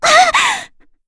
FreyB-Vox_Damage_03.wav